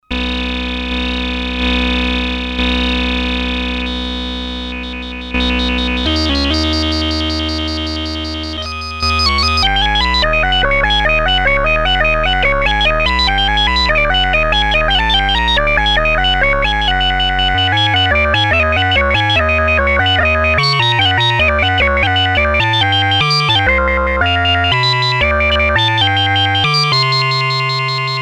Portable analog synthesizer (but digital oscillator) similar to Electro harmonix Mini-Synthesizer or EMS synthi.
Class: Synthesizer
Polyphony: 1 note